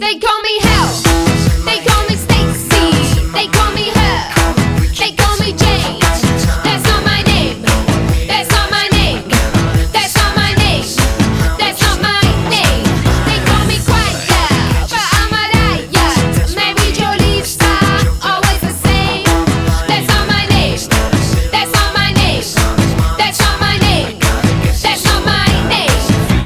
веселые